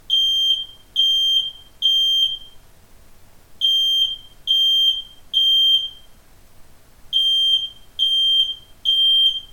• Hitzewarnmelder (nicht vernetzbar)
• Lautstärke im Test: 93,2 dBA
smartwares-rm127k-hitzemelder-alarm.mp3